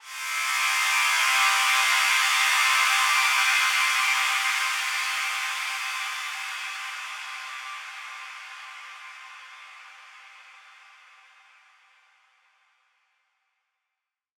SaS_HiFilterPad06-C.wav